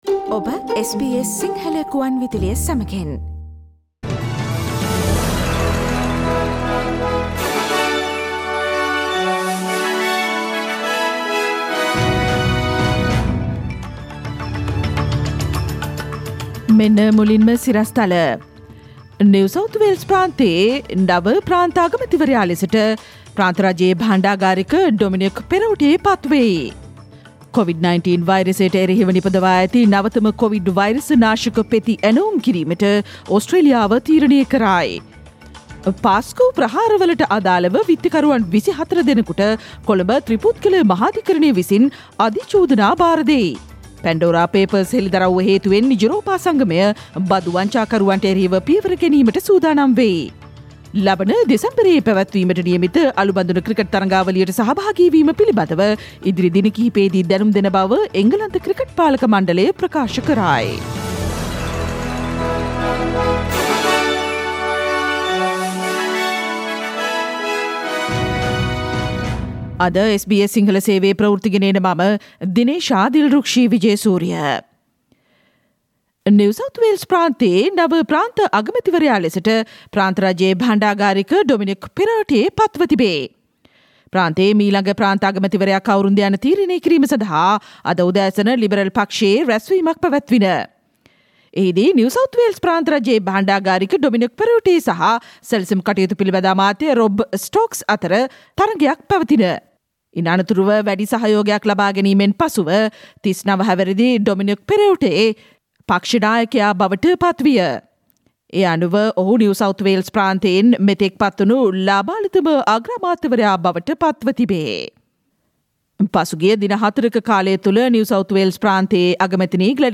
ඔක්තෝම්බර් මස 5 වන අඟහරුවාදා වැඩසටහනේ ප්‍රවෘත්ති ප්‍රකාශයට සවන්දෙන්න ඉහත චායාරූපය මත ඇති speaker සලකුණ මත click කරන්න